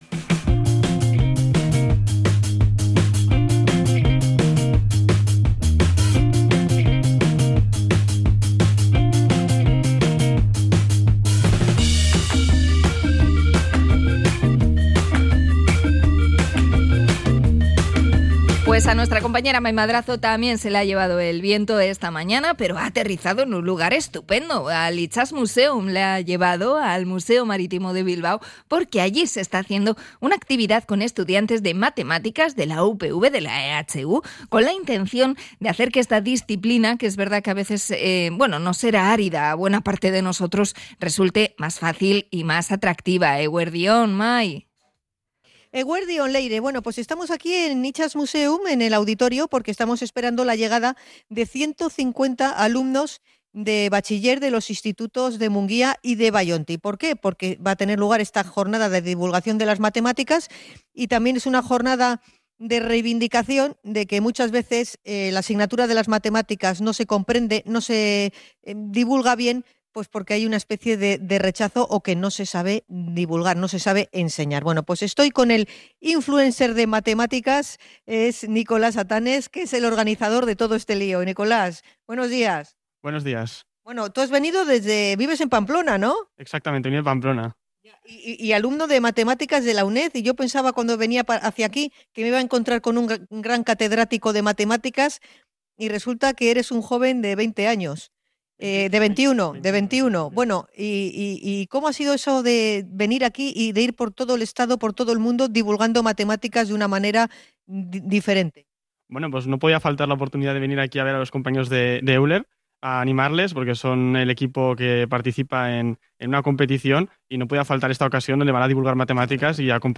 alumnos de matemáticas de la EHU